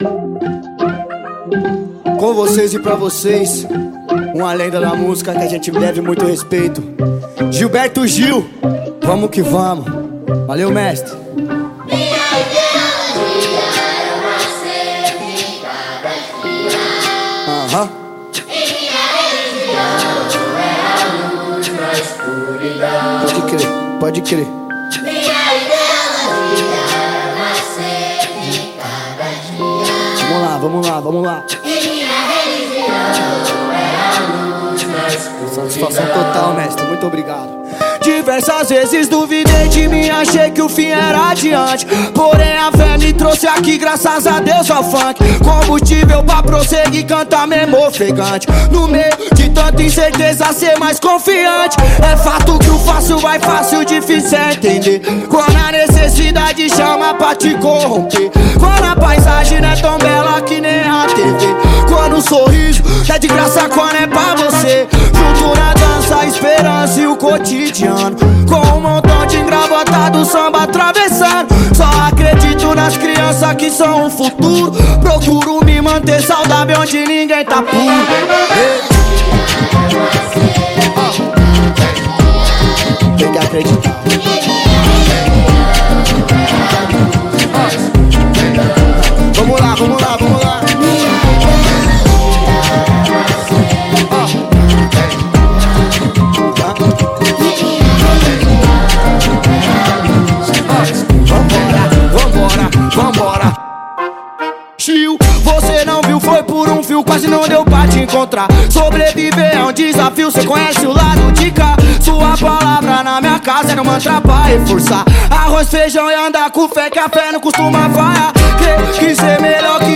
(Ao vivo)
2024-09-12 23:45:59 Gênero: MPB Views